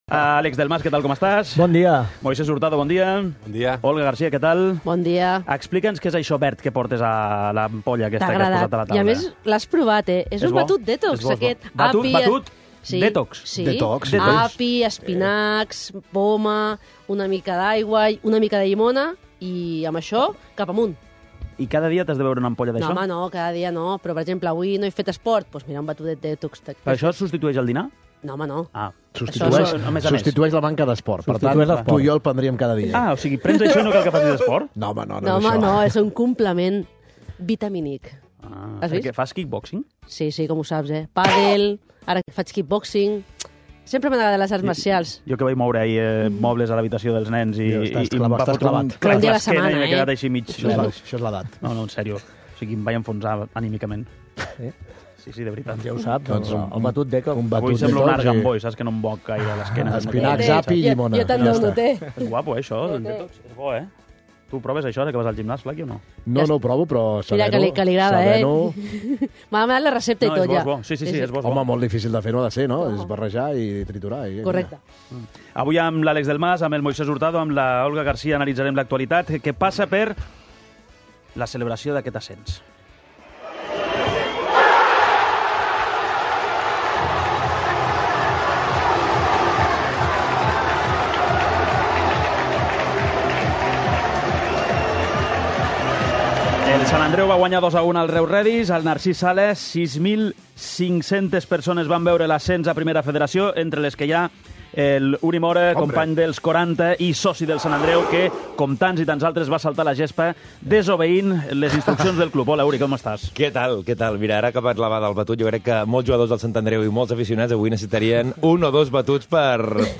Al 'Què t'hi Jugues!' fem tertúlia amb el trident